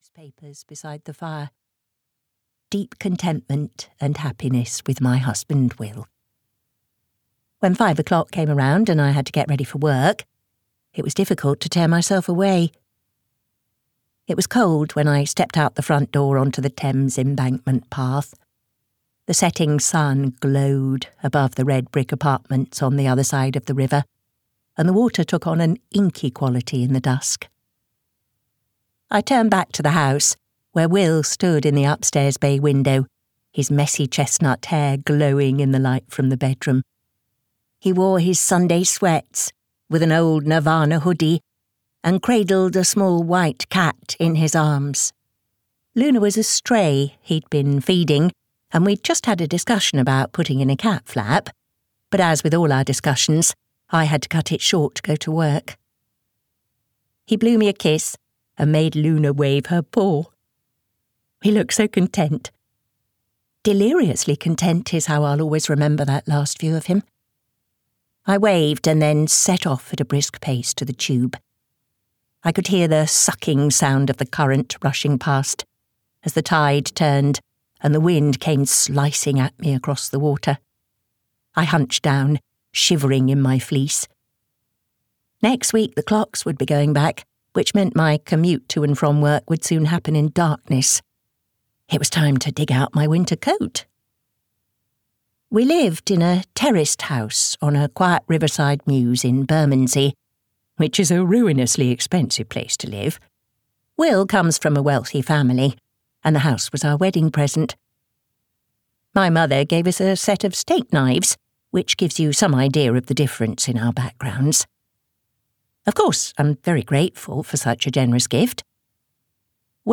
Fear The Silence audiokniha
Ukázka z knihy